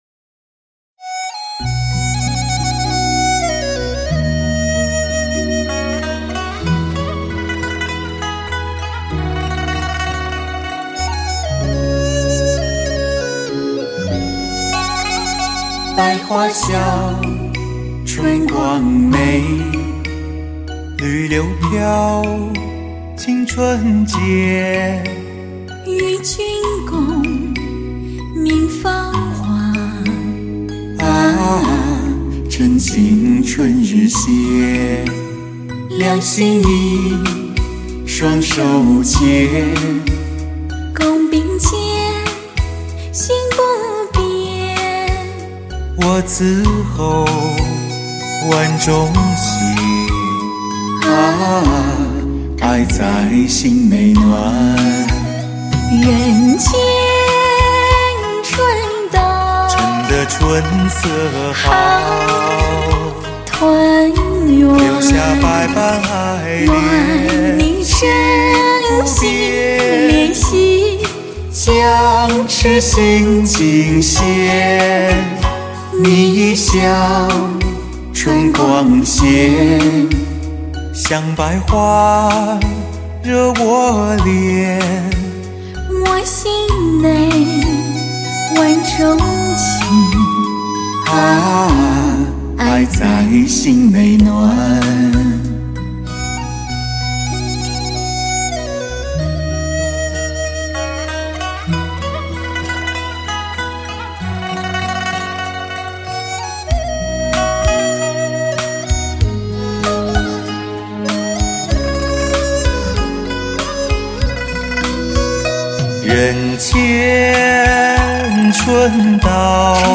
30首甜蜜对唱情歌演绎男女间的情感世界